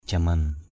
/ʥa-mʌn/ 1.
jaman.mp3